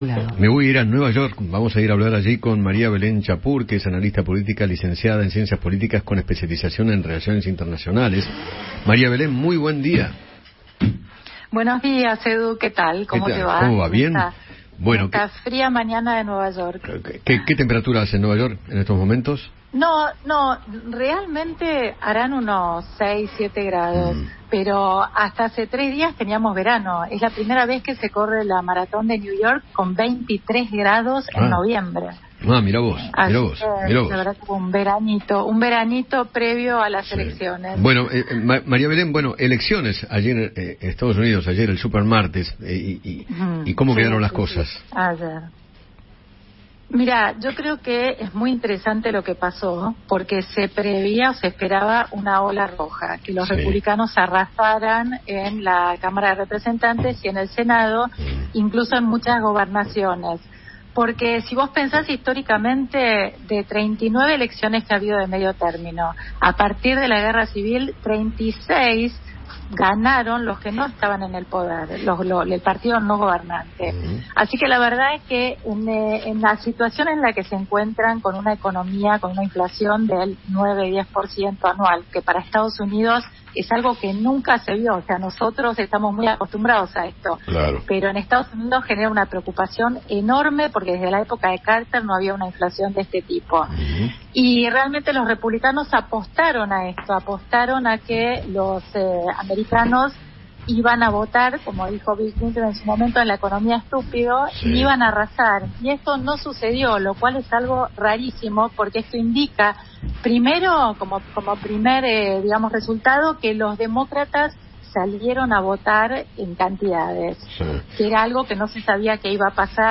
analista internacional